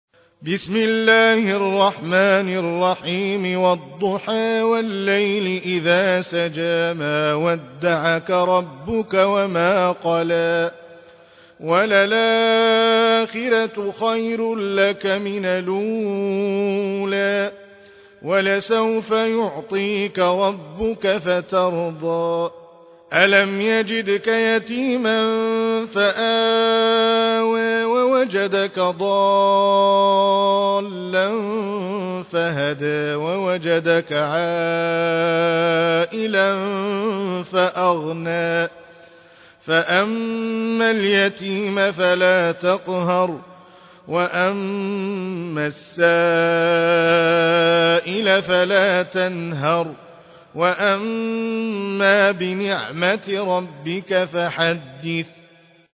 Récitation